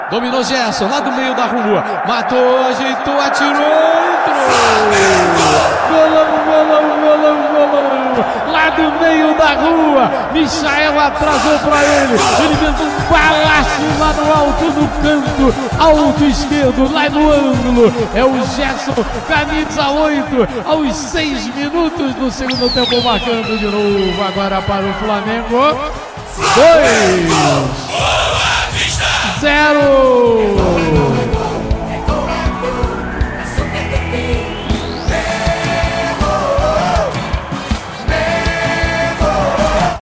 Ouça os gols da vitória do Flamengo sobre o Boavista com a narração de José Carlos Araújo